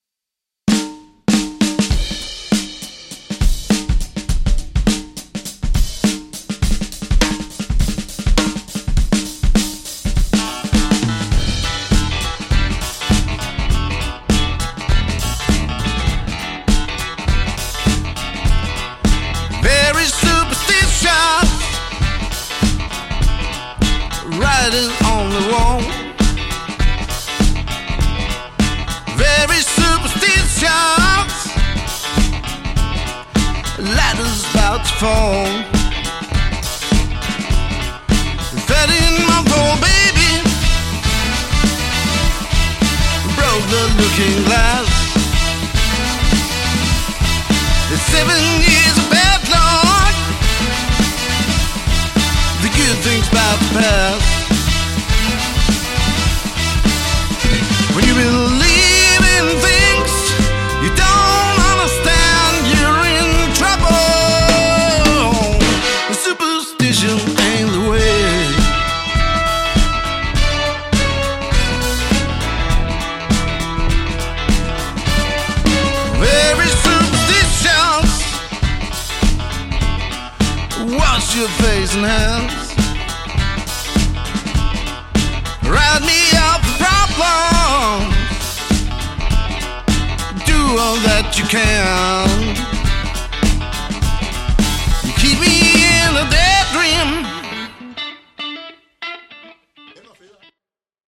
LIVE Koncert
• Coverband